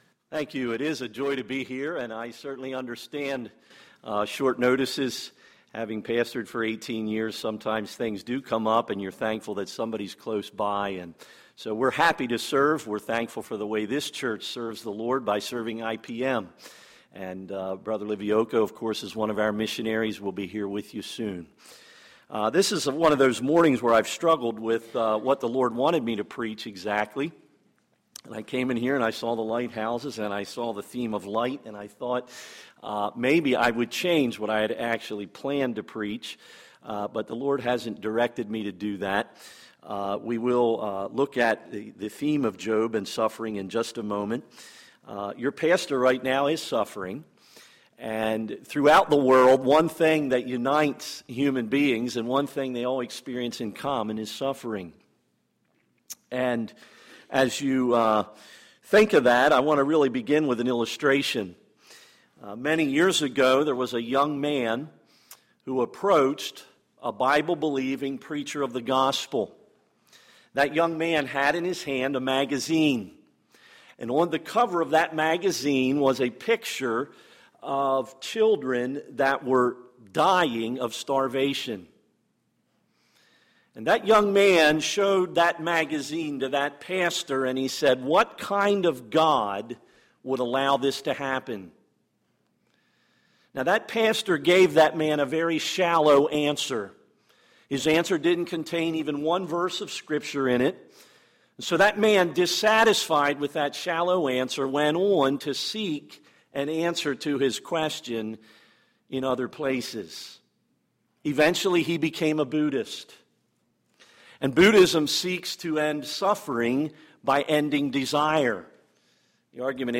Sunday, September 9, 2012 – Morning Message